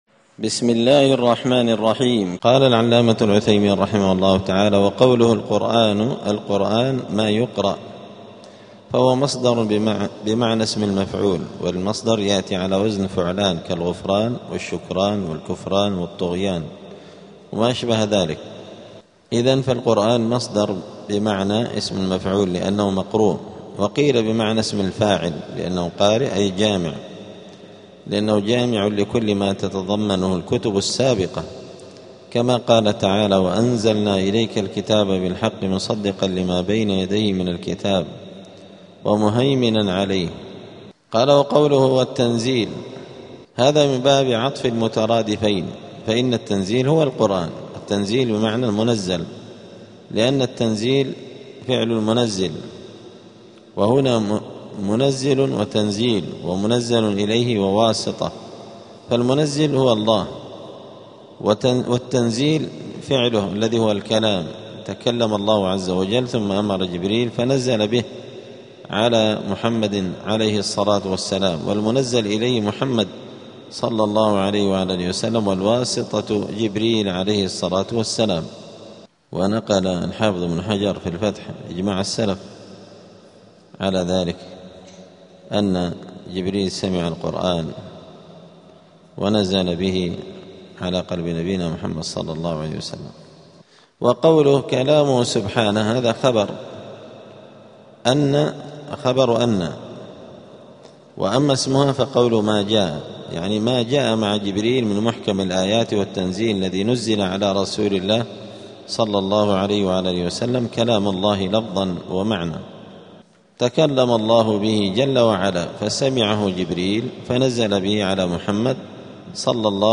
دار الحديث السلفية بمسجد الفرقان قشن المهرة اليمن
39الدرس-التاسع-والثلاثون-من-شرح-العقيدة-السفارينية.mp3